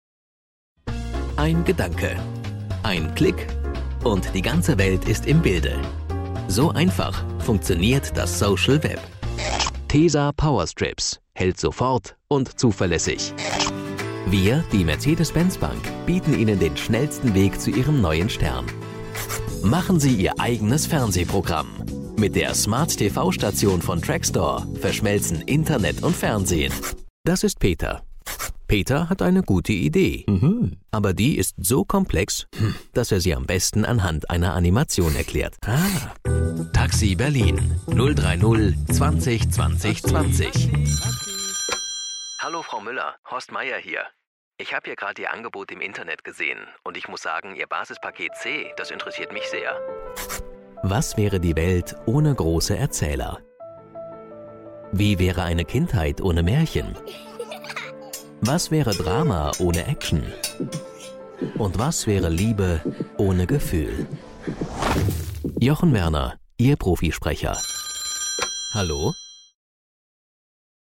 Studioausstattung: Desone/Soundblocker Kabine, Neumann TLM 103, Gefell M930, UA LA-610 Vollröhrenpreamp, GAP pre-73 MKII, RME Fireface, Genelec, Musiktaxi
Freundlich verbindliche Stimme.
Sprechprobe: Industrie (Muttersprache):